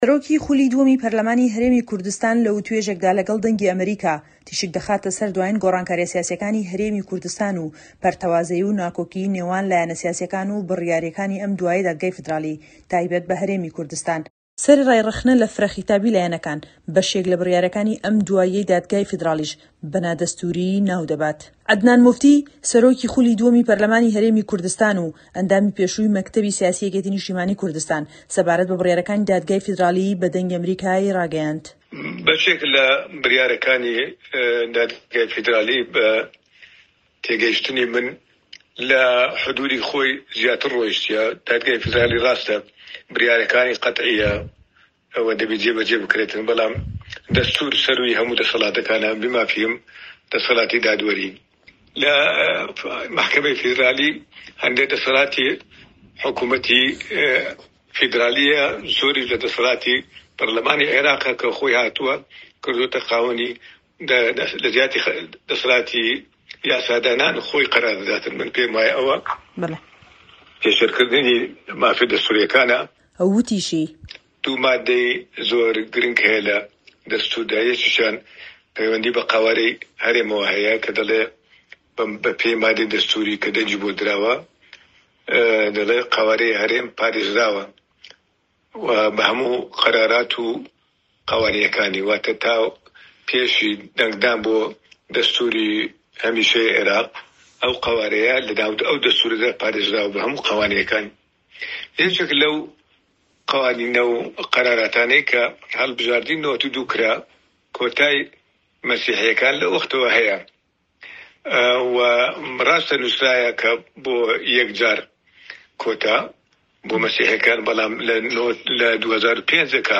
سەرۆکی خولی دووەمی پەرلەمانی هەرێمی کوردستان لە وتووێژێکدا لەگەڵ دەنگی ئەمەریکا تیشک دەخاتە سەر دواین گۆڕانکاریە سیاسیەکانی هەرێمی کوردستان و پەرتەوازەیی و ناکۆکی نێوان لایەنە سیاسیەکان و بڕیارەکانی ئەم دواییەی دادگای فیدڕاڵی تایبەت بە هەرێمی کوردستان.